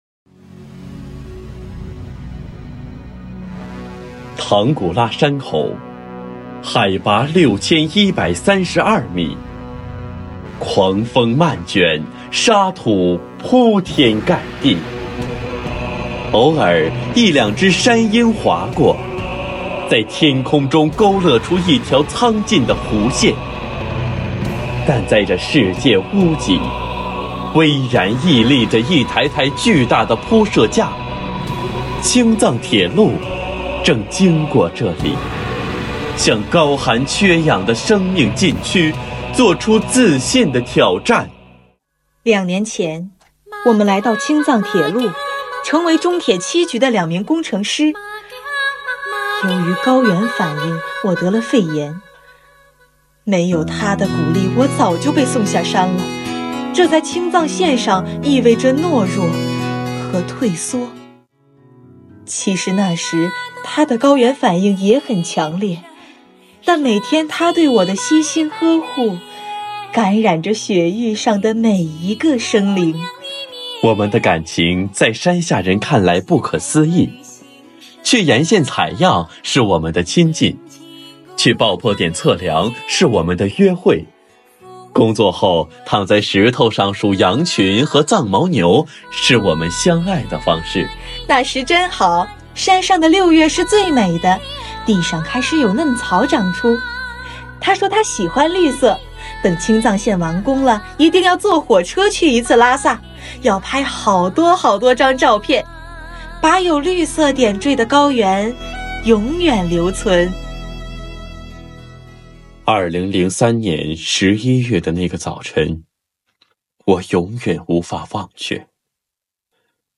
诵中华经典，品古韵流芳----中华经典诵读大赛圆满落幕
团体赛铜奖：